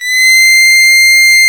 STRS C6 S.wav